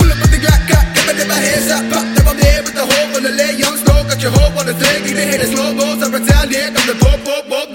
Glock Cocked.wav